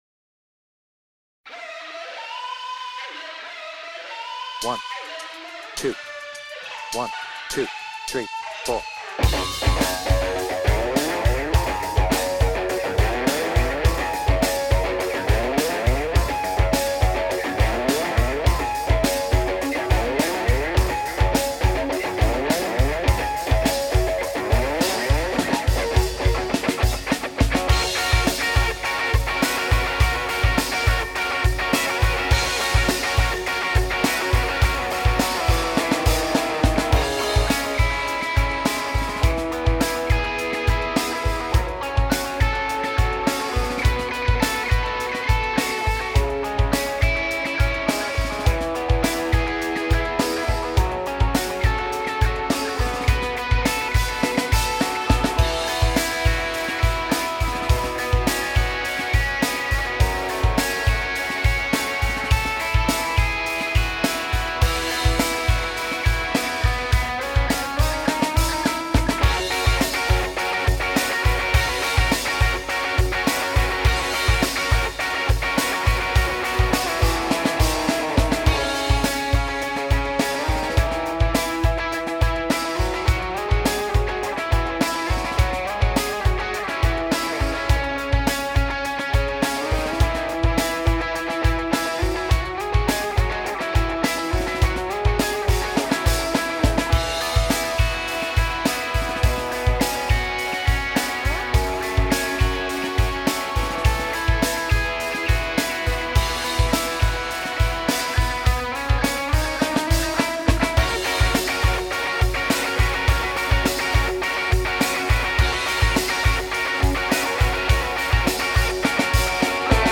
BPM : 104
Without vocals